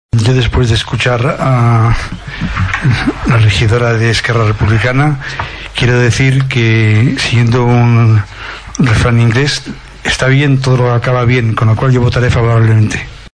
El regidor no adscrit a cap grup, Jose Carlos Villaro, es va mostrar a favor de l’aprovació del servei.